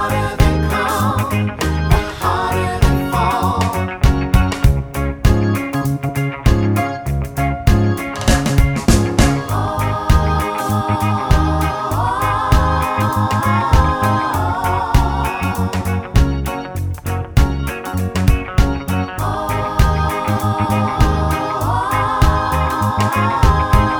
no Backing Vocals Reggae 3:10 Buy £1.50